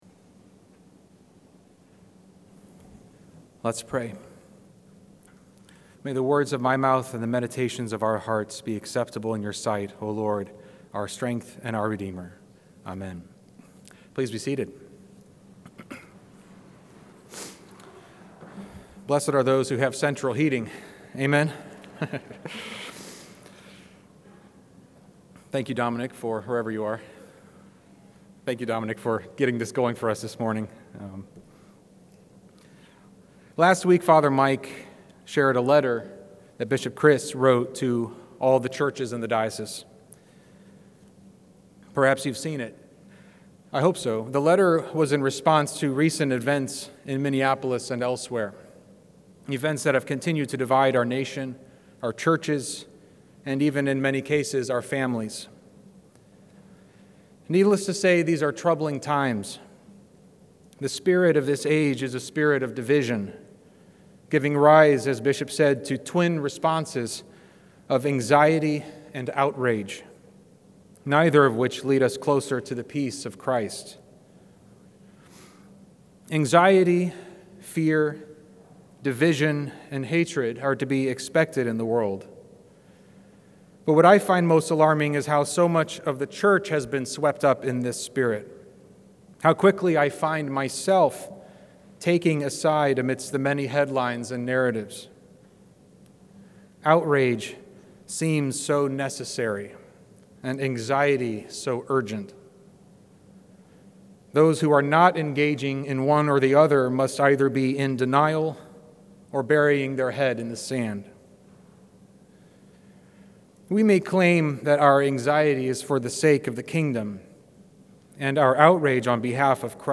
2026 Current Sermon 4th Sunday of Epiphany